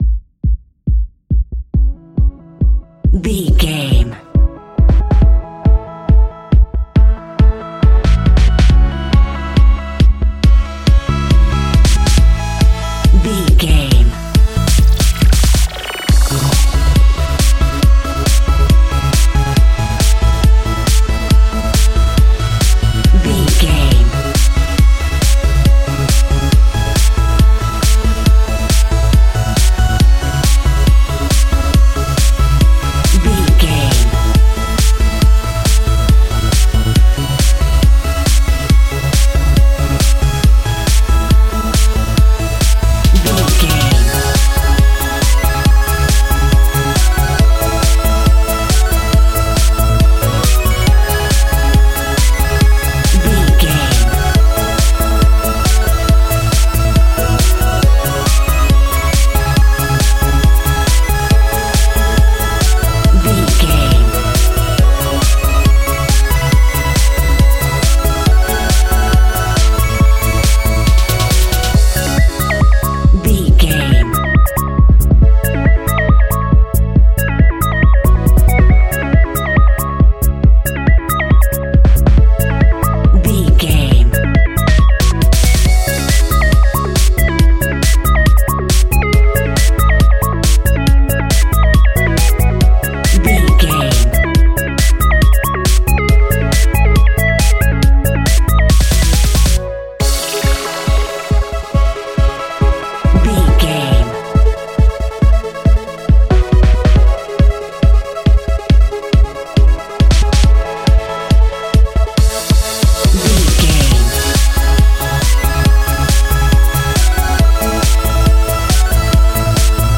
Aeolian/Minor
C#
futuristic
frantic
driving
energetic
epic
groovy
drums
drum machine
synth leads
electronic music
techno music
synth bass
synth pad
robotic